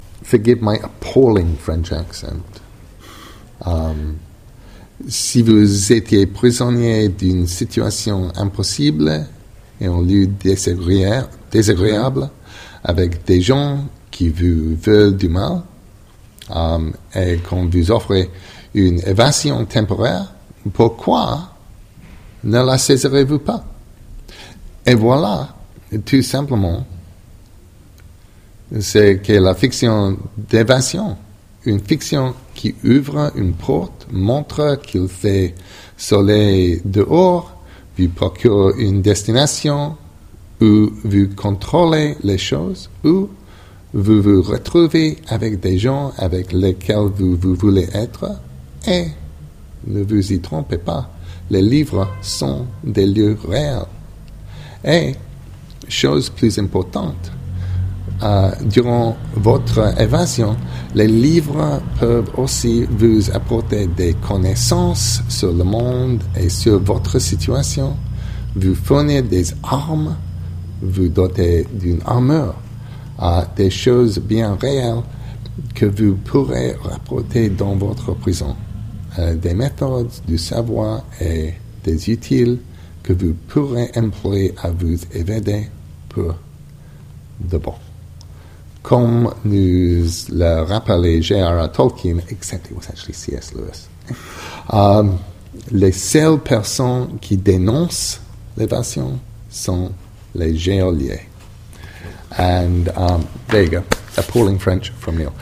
[Neil Gaiman nous gratifie ici d'une lecture en français d'un extrait de son essai sur les bibliothèques et la lecture, que vous pouvez découvrir ci-dessous].